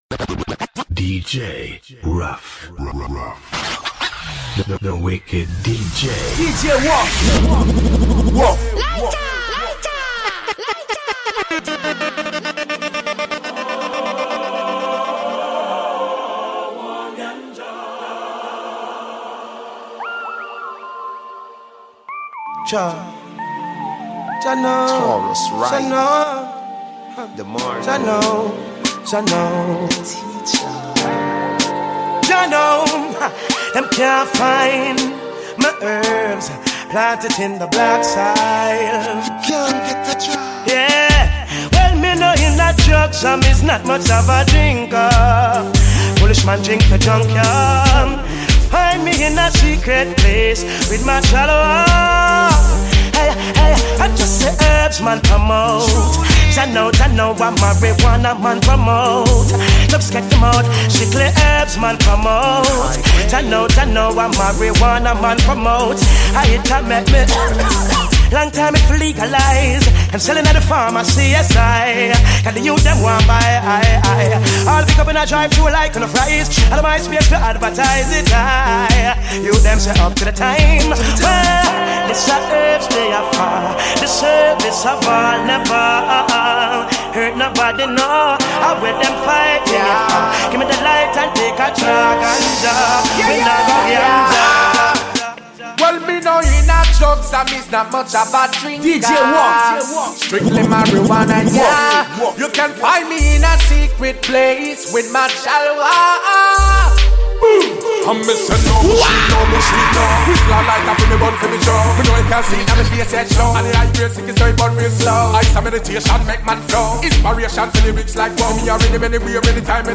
Genre: Mixtape.